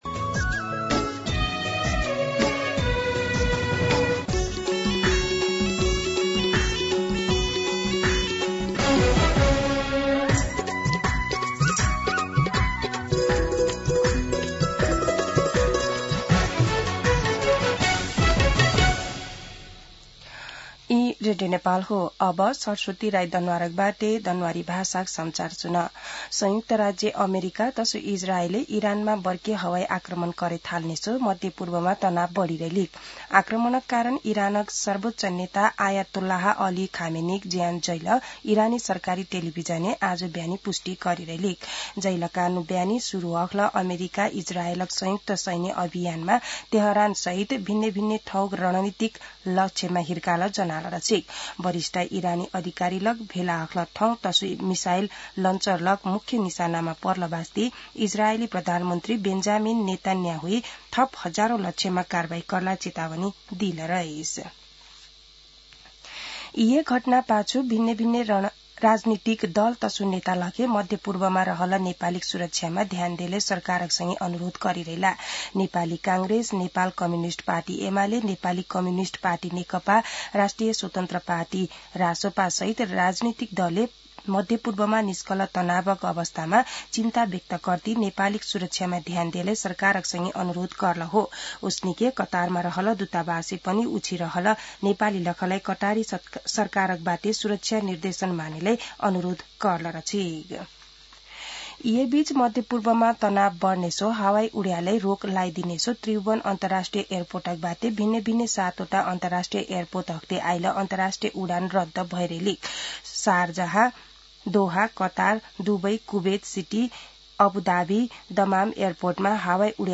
दनुवार भाषामा समाचार : १७ फागुन , २०८२
Danuwar-News-17.mp3